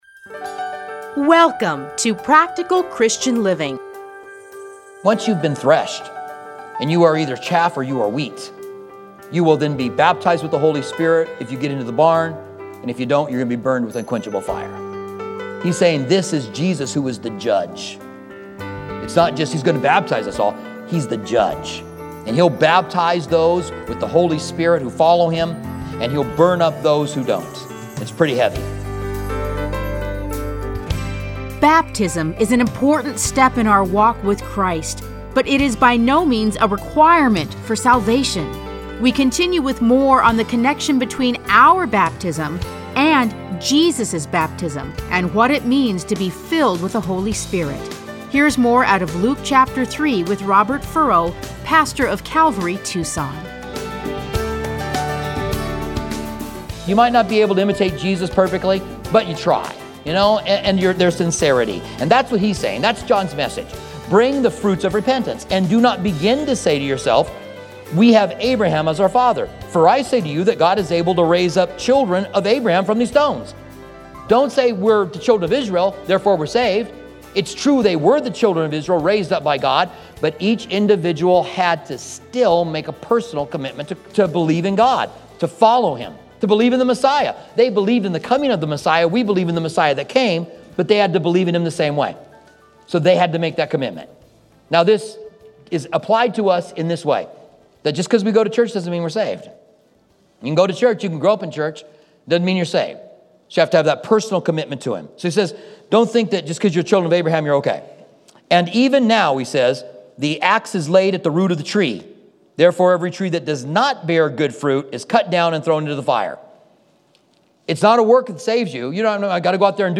Listen to a teaching from Luke 3.